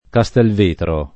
kaStHlv%tro] top. (E.-R.) e cogn. — due comuni: C. di Modena e C. Piacentino — pn. etimologica con -e- aperta, come in Castelvetere (l’uno e l’altro dal lat. vetus «vecchio» con -e- breve); ma qui, diversam. da Castelvetere, andata in disuso fino almeno dal ’700 per l’attraz. di vetro — cfr. Vietri